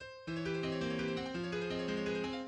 No. 2 F minor